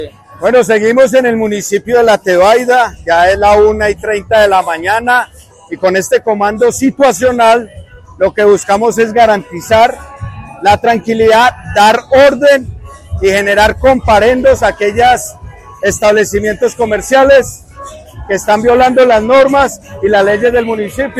Audio del: Comandante de Policía Quindío – Carlos Mario Bustamante.